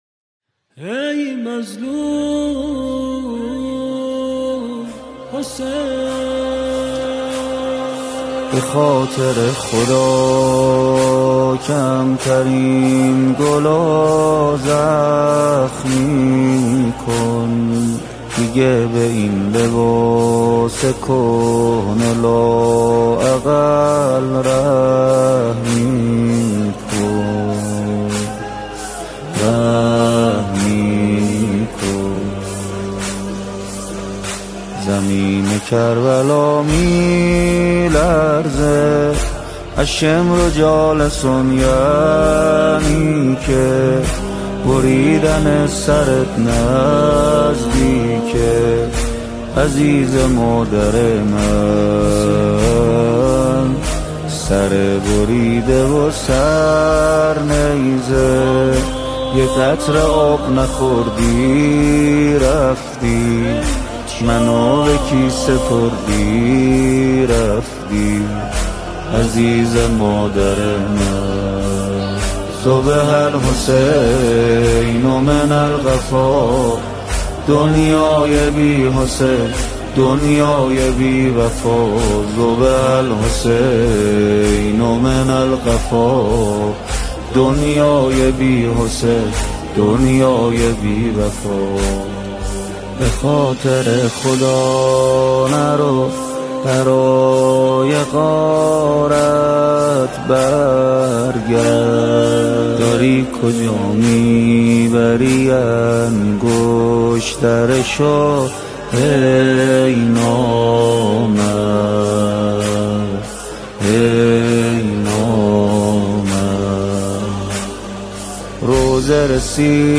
با نوای زیبا و دلنشین